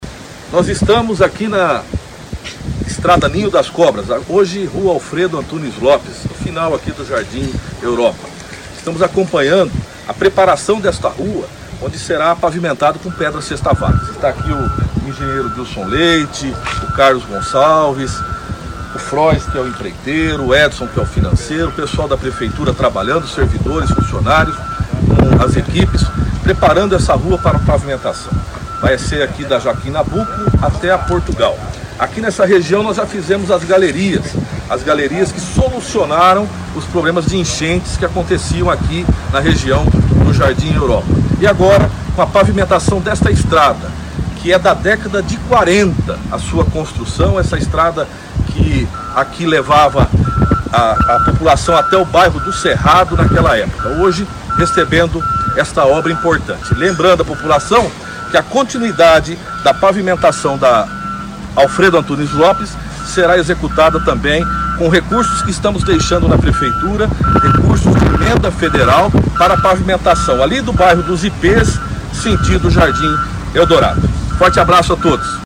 Ouçam áudio de Jorge Duran falando sobre a obra e entenda.
duran-falando.mp3